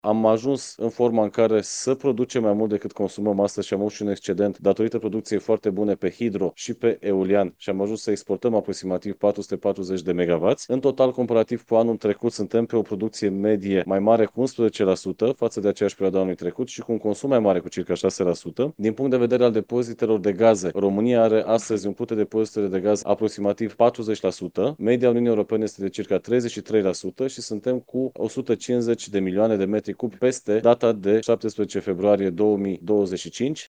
Ministrul Energiei, Bogdan Ivan, după ședința Comandamentului Energetic Național: „Suntem pe o producție medie mai mare cu 11% față de aceeași perioadă a anului trecut”